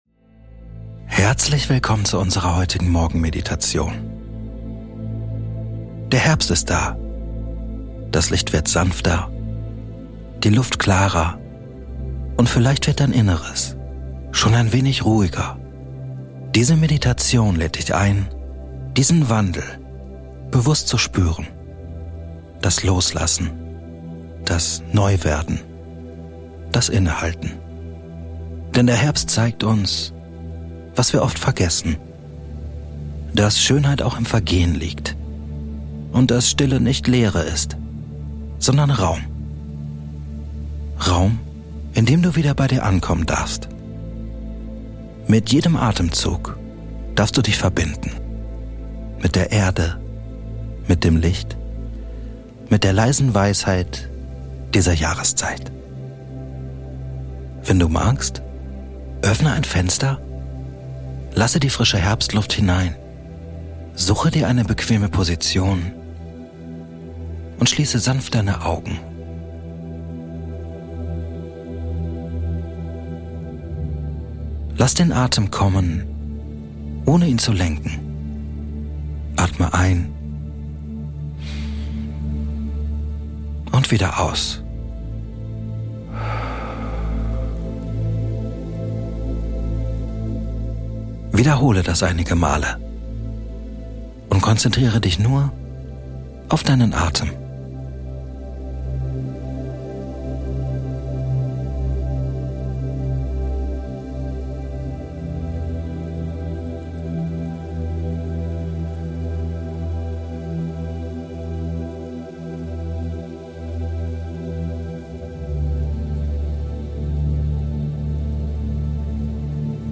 Diese geführte Herbstmeditation begleitet dich sanft in den Morgen und schenkt dir einen Moment tiefer Achtsamkeit und innerer Ruhe.